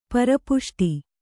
♪ para puṣṭi